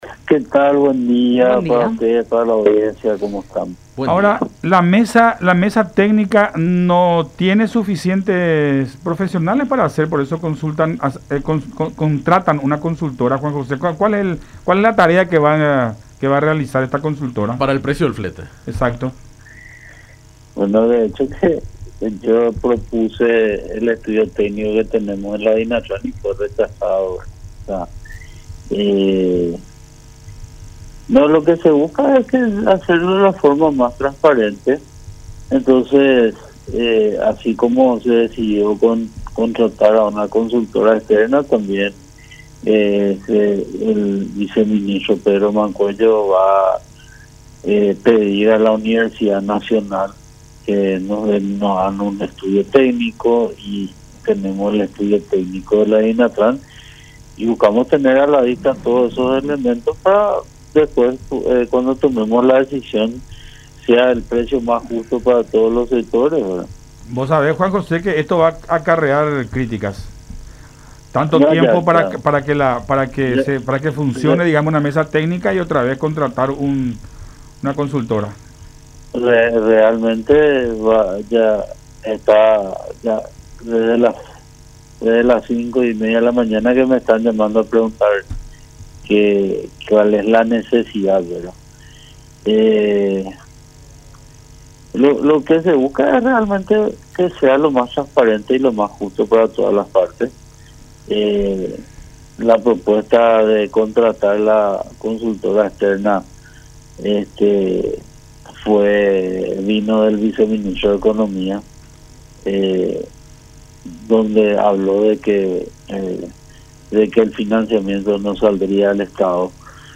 “Así como se decidió contratar a una consultora externa, también el viceministro (de Comercio y Servicios) Pedro Mancuello solicitará a la Universidad Nacional de Asunción (UNA) que nos hagan un estudio técnico, que se va a sumar al estudio técnico que ya tenemos acá en la DINATRAN. Buscamos tener a la vista todos estos elementos para que sea lo más justo y transparente para todas las partes”, explicó Vidal en diálogo con Enfoque 800 por La Unión.